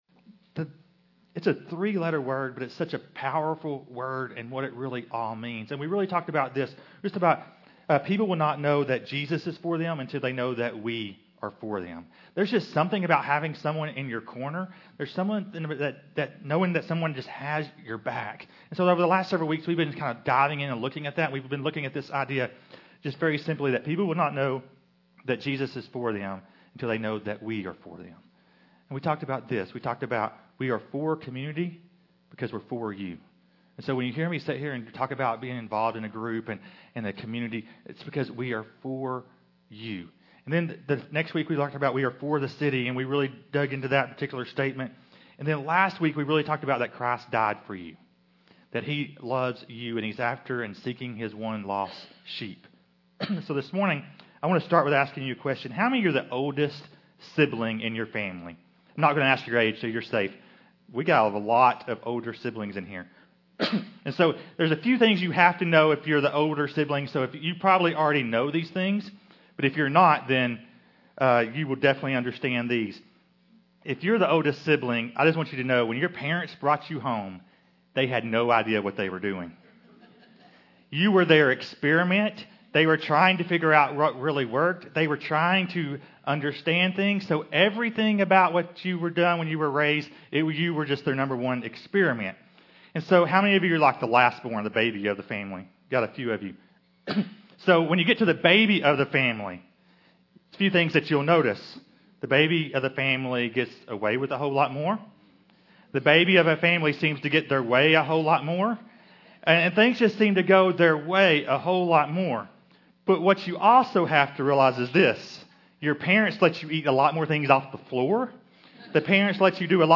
In this sermon series we are going to look at topics like how to connect with unbelievers, how serving others can open their hearts to the gospel, and how paying attention to our own spiritual growth can strengthen our witness.